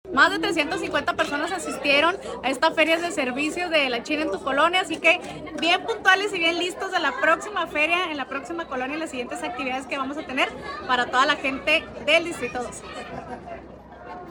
ESCUCHA AQUÍ EL MENSAJE DE NANCY FRÍAS